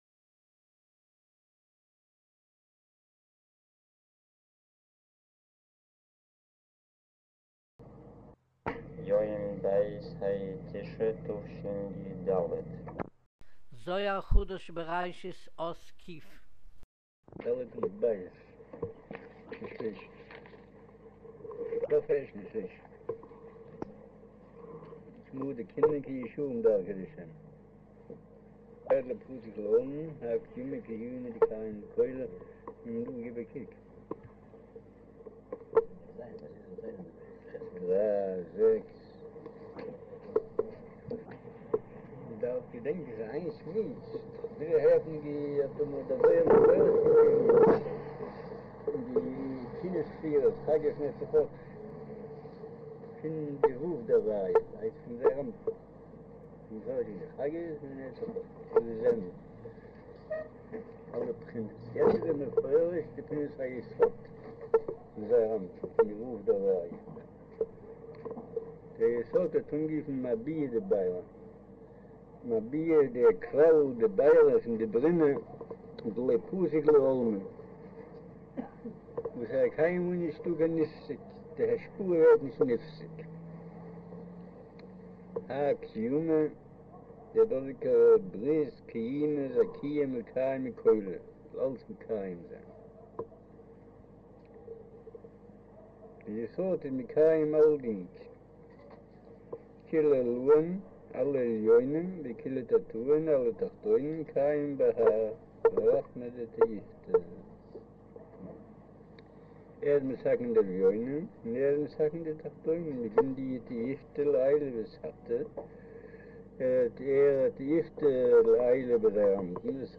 אודיו - שיעור מבעל הסולם זהר חדש בראשית אות ק' - קב'